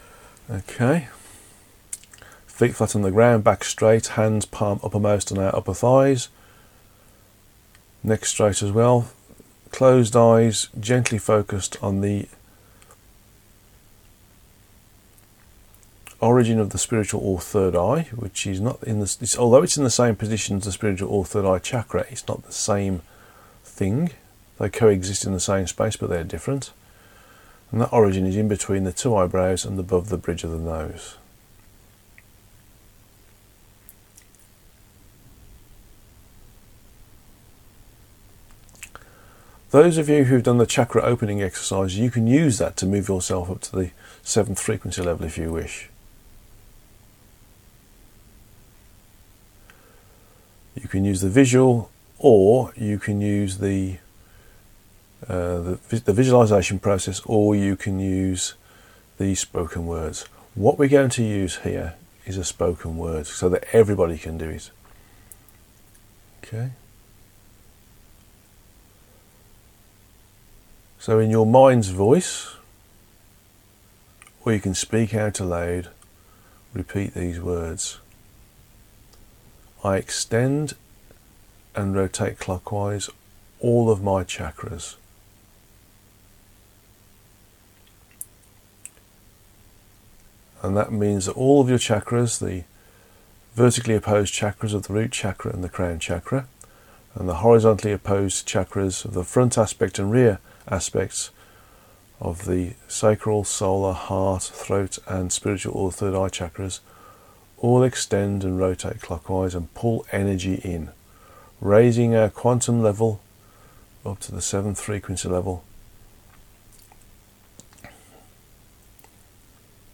Covid-19-meditation.mp3